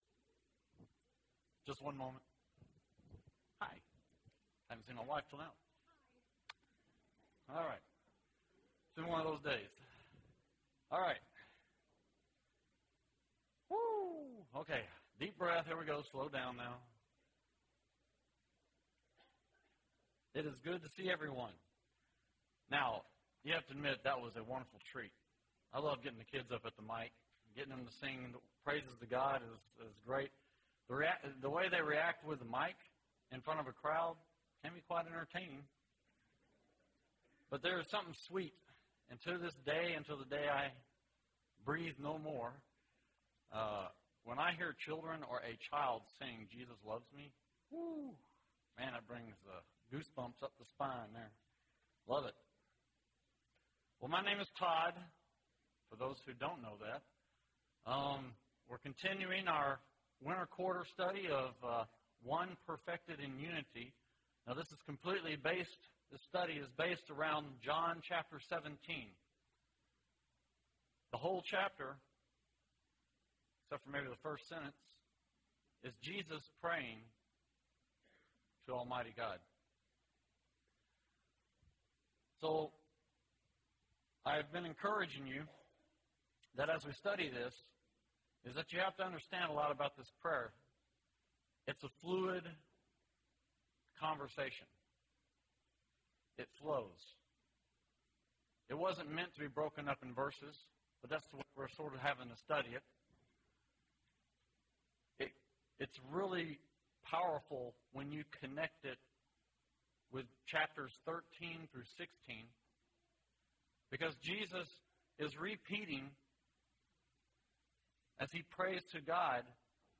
Wednesday PM Bible Class